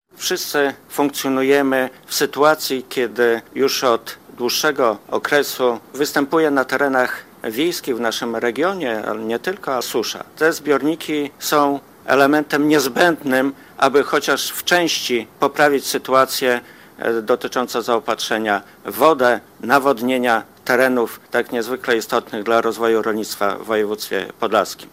Bogdan Dyjuk, członek zarządu województwa podlaskiego, podczas podpisywania umów podkreślał jak ważne są to inwestycje, szczególnie na terenach rolniczych: